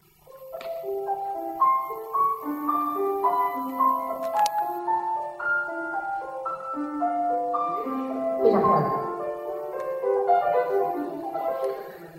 [120-123]这是在音乐史上非常重要的段落，海顿在这里写了踏板，就象贝多芬Waldstein那段一样，这是不换踏板的：